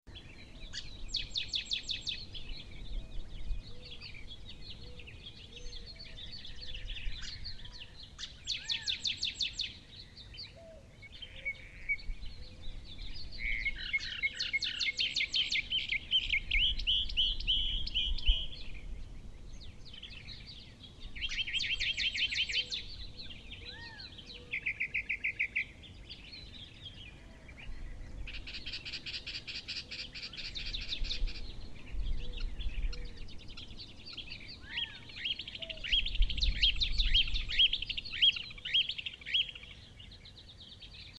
Bird
music_bird.C5ackVVk.mp3